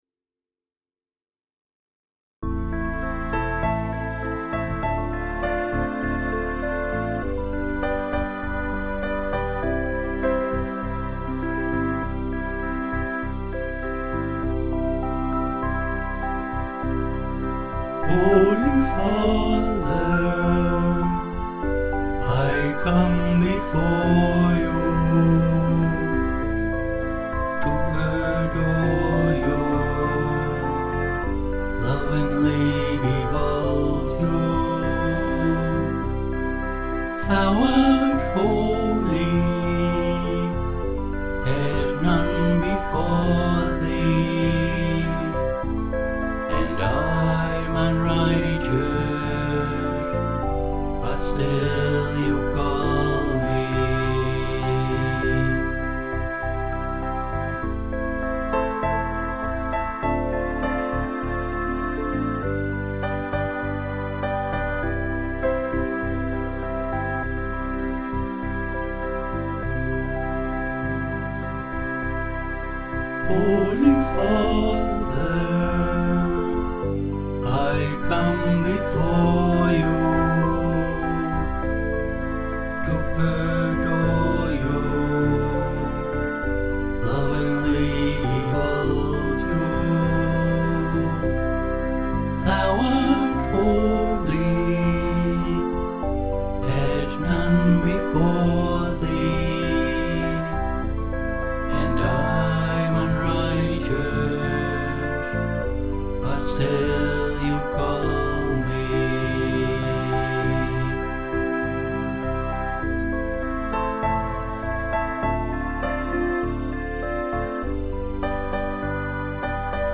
Prayerfully